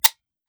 fps_project_1/45 ACP 1911 Pistol - Dry Trigger 003.wav at 9bcc51fe91cecde9dcd62d2e163db10327fcf5ed